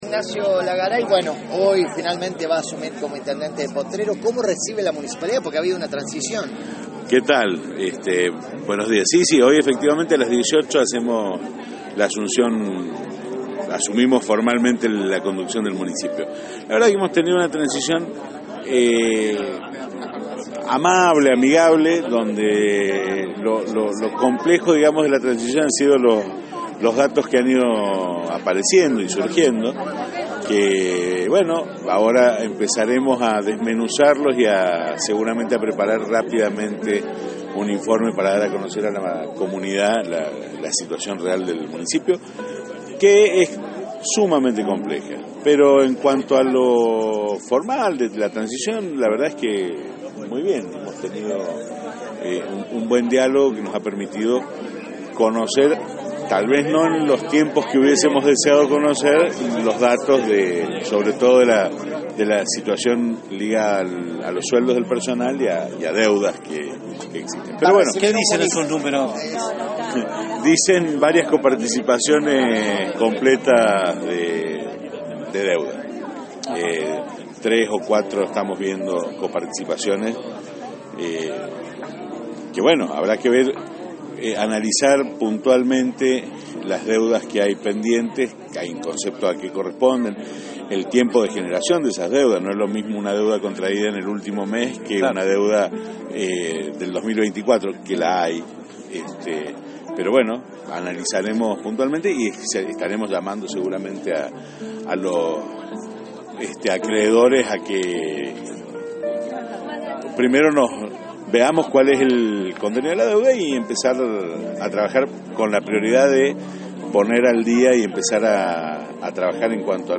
Durante la entrevista que mantuvo esta mañana en Juana Koslay con periodistas locales, Olagaray trazó un panorama sin eufemismos.
nacho-olagaray.mp3